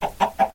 mob / chicken / say1.ogg
should be correct audio levels.